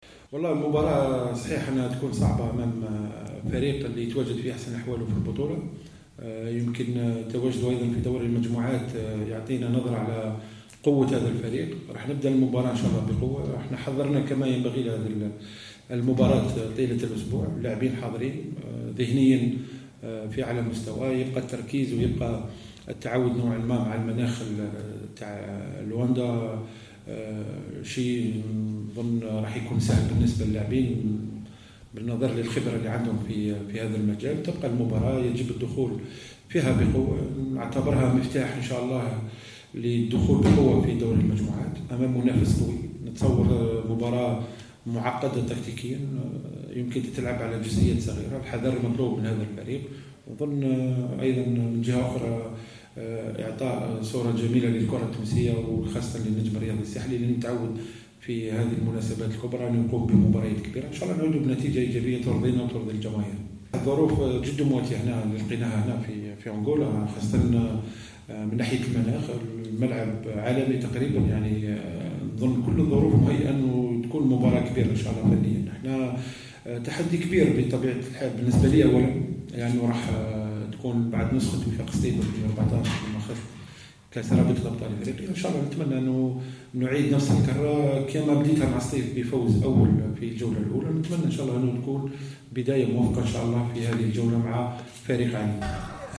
عقد مدرب النجم الساحلي خير الدين مضوي ندوة صحفية للحديث عن مواجهة بريميرو دو أغسطو الأنغولي يوم السبت 05 ماي 2018 بداية من الساعة الخامسة مساء في إطار الجولة الأولى من منافسات مرحلة المجموعات من رابطة الأبطال الإفريقية لكرة القدم.